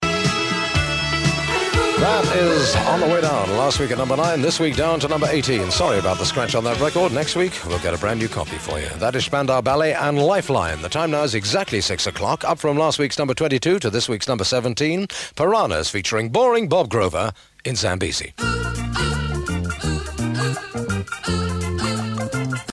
Apologising for a scratched record